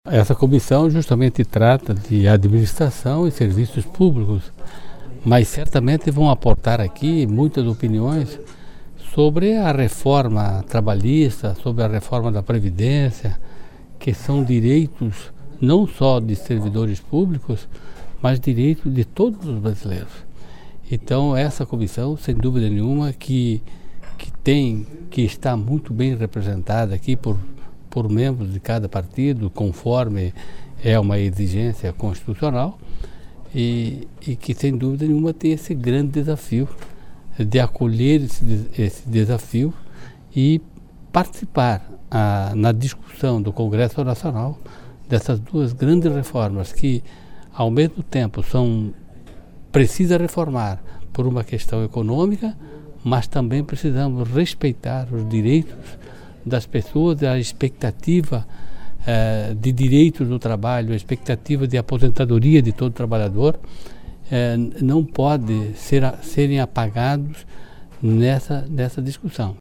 Ouça abaixo o que disseram os presidentes das demais comissões instaladas nesta quarta-feira:
Serafim Venzon (PSDB) - Comissão de Trabalho, Administração e Serviço Público